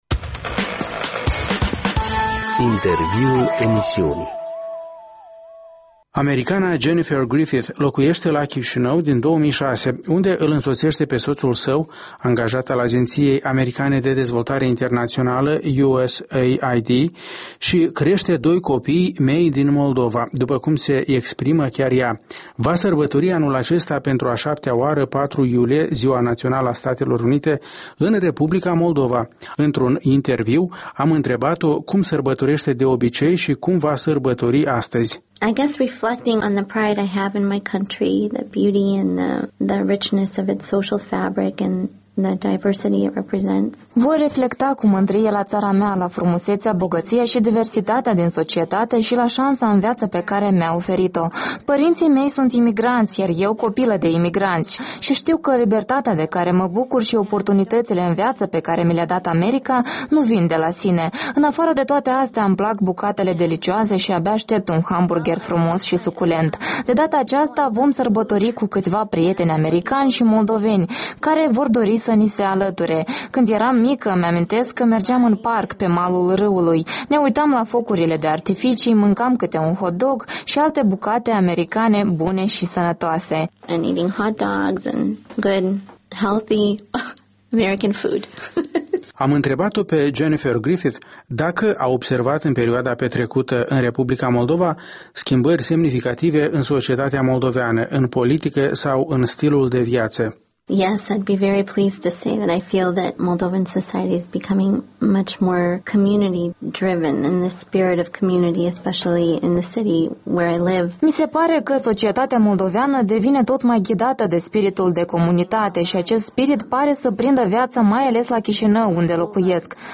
Într-un interviu, am întrebat-o cum sărbătoreşte de obicei şi cum va sărbători astăzi: